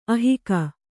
♪ ahika